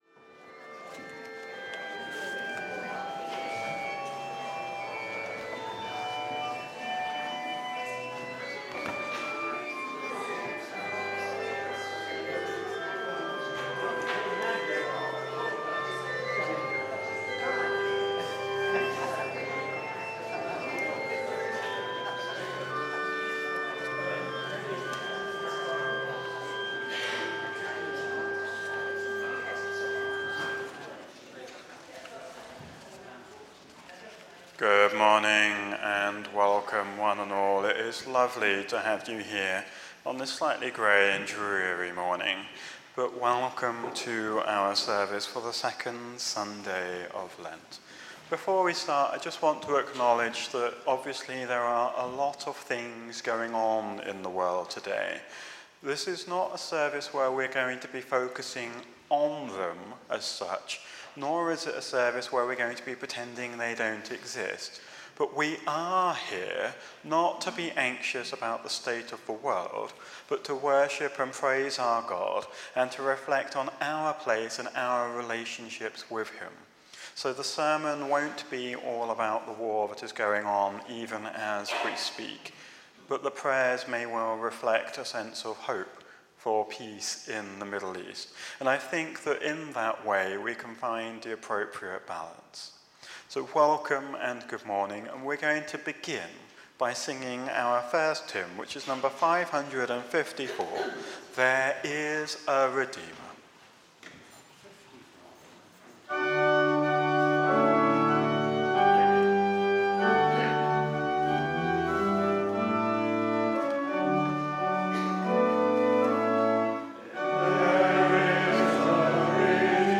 Sunday Audio Service – Lent 2 – Holy Communion – 01/03/26